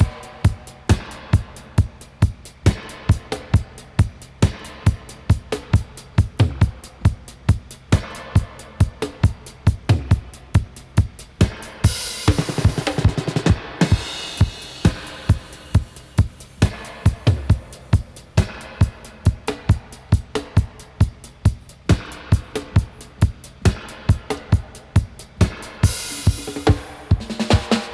136-DUB-02.wav